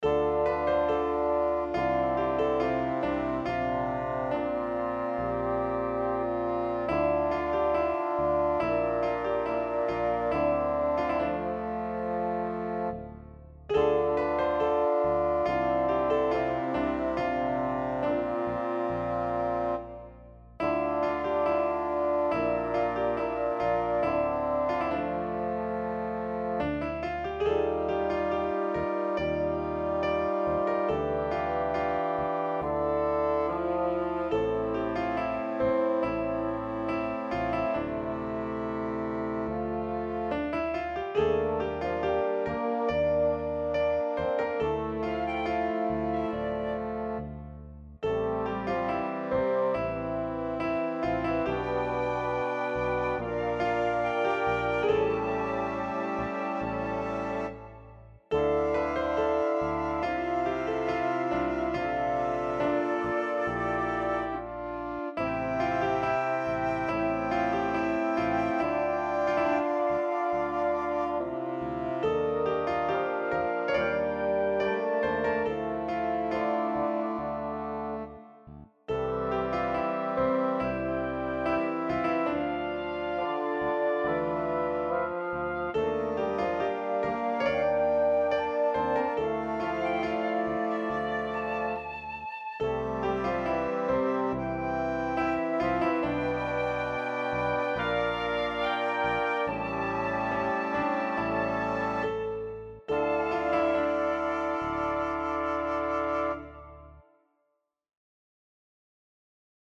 Modern Classical Composer/Artist
Underscore
Reduced arrangement